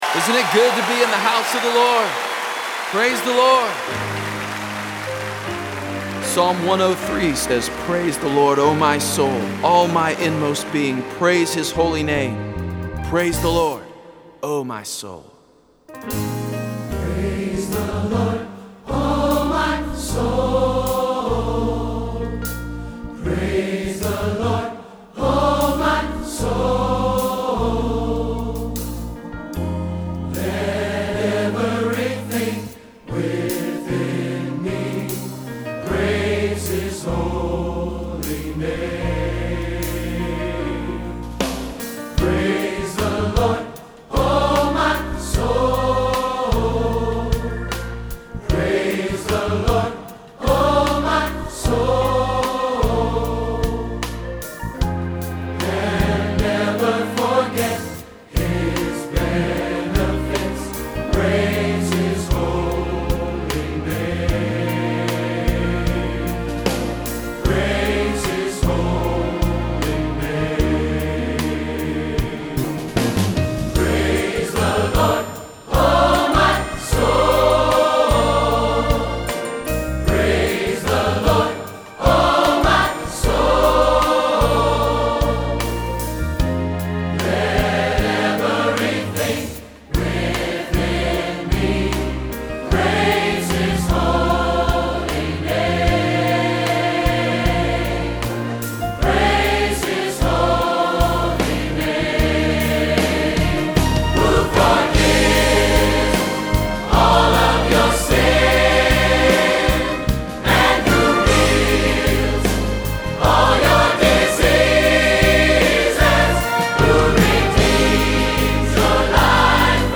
WORSHIP CHOIR AND ORCHESTRA
Click this link to download the MP3 practice file: Praise The Lord (Psalm 103)